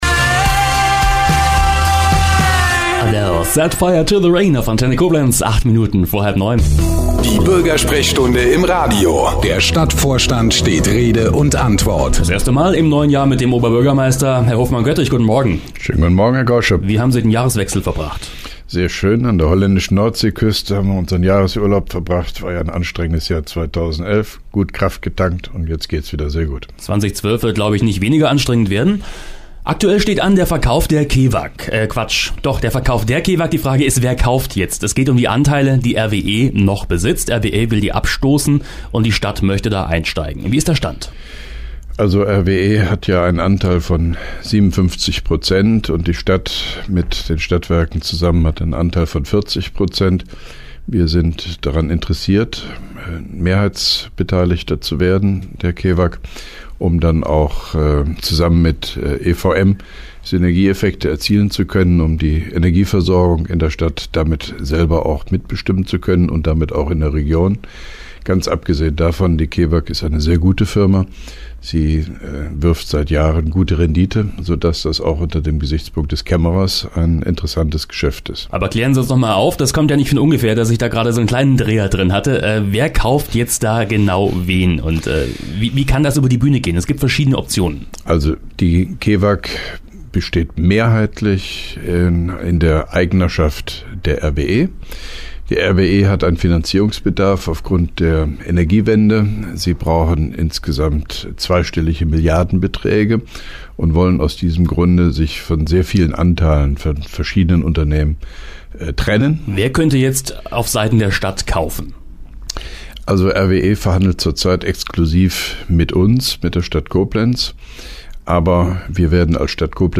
(1) Koblenzer Radio-Bürgersprechstunde mit OB Hofmann-Göttig 17.01.2012